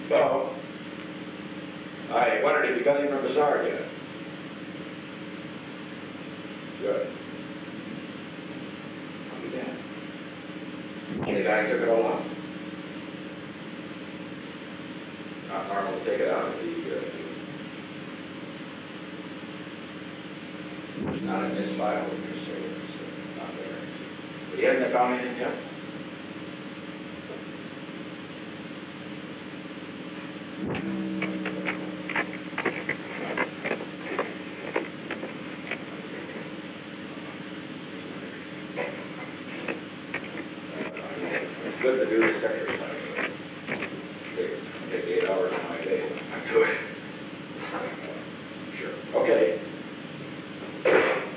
Secret White House Tapes
Conversation No. 442-26
Location: Executive Office Building
The President talked with Alexander M. Haig, Jr.